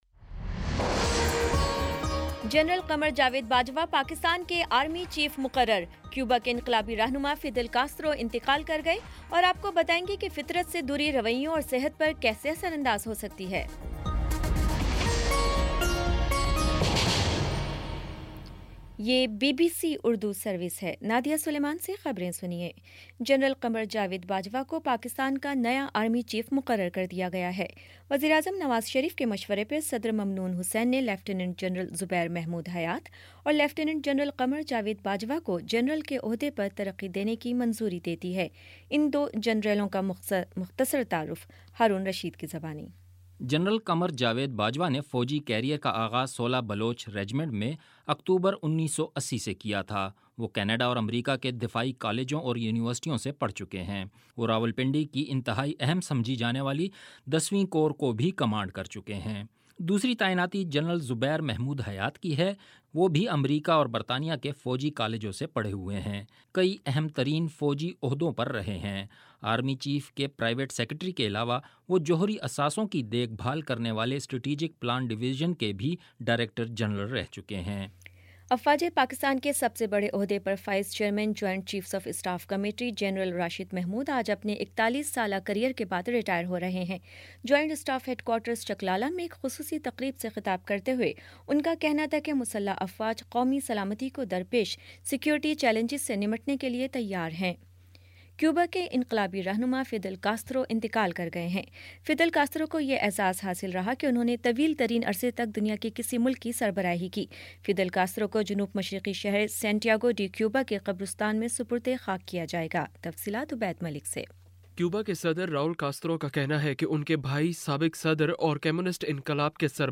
نومبر 26 : شام سات بجے کا نیوز بُلیٹن